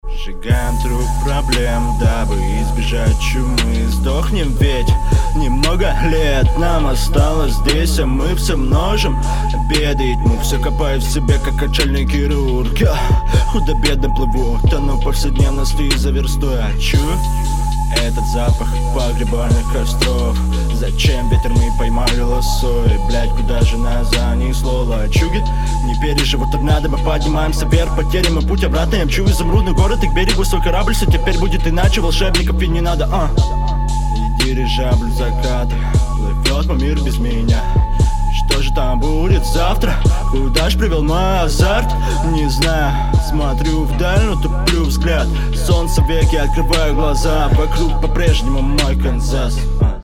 Очень слабая подача.
Средний трек, читка выделяется, но в целом всё довольно серо